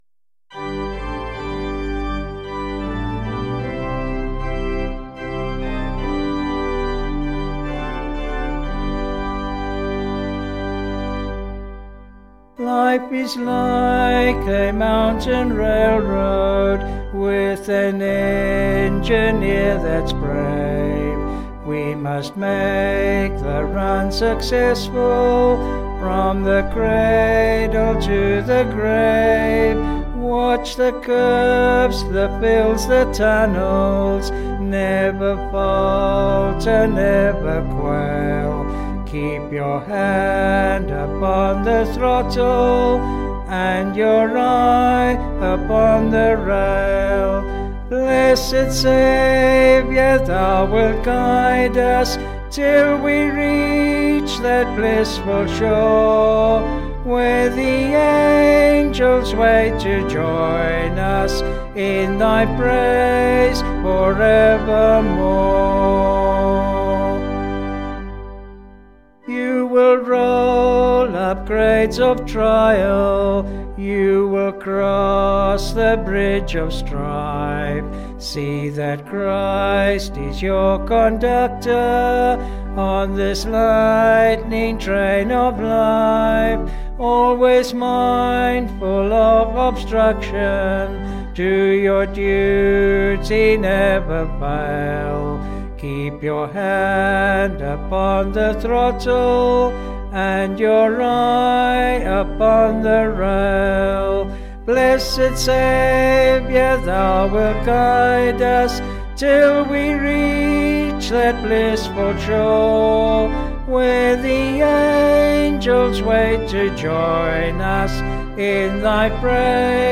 Vocals and Organ   265.7kb Sung Lyrics